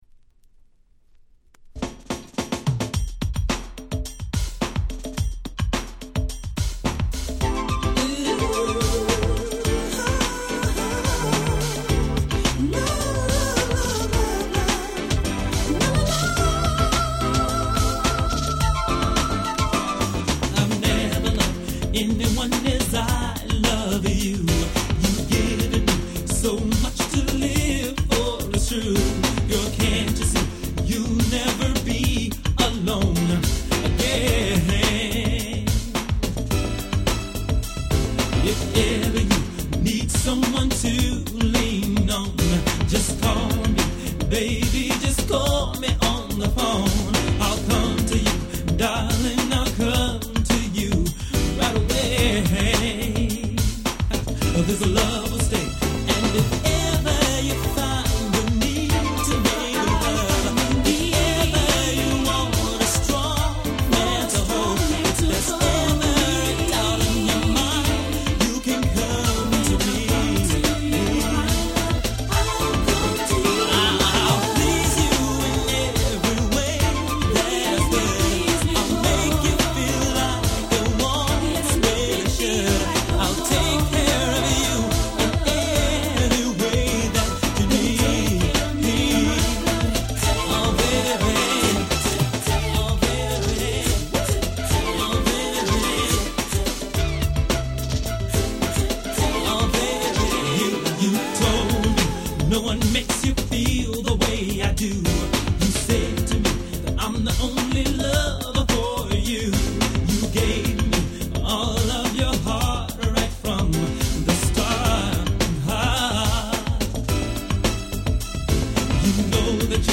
92' Nice マイナーR&B !!!
彼のシングルってほとんどHouseばかりなのですが、こちらはなんとSmoothでGroovyなR&B良曲なのです！
New Jack系なんかともなかなか合います！